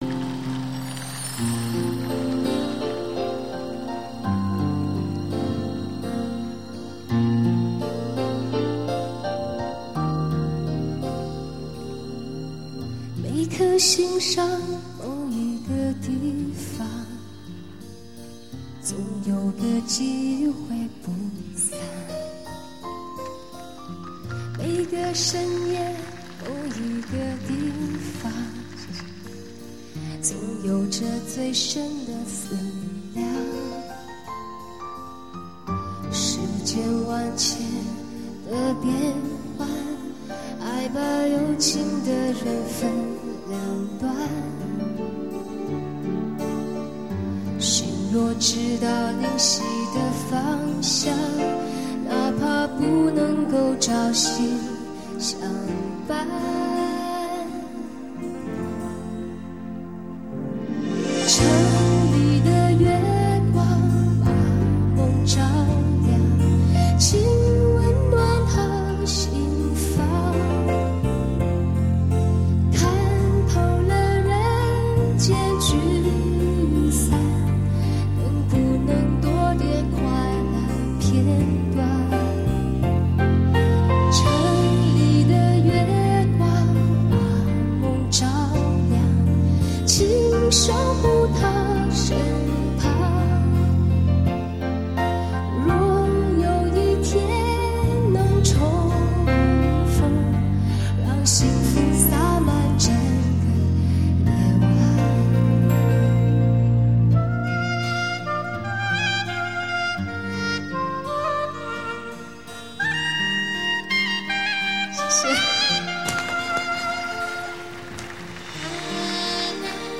全部歌曲均由原唱歌星亲自主唱及参与歌曲录影制作
试听是左唱右伴